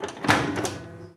Abrir la puerta de una lavadora 2
lavadora
Sonidos: Acciones humanas
Sonidos: Hogar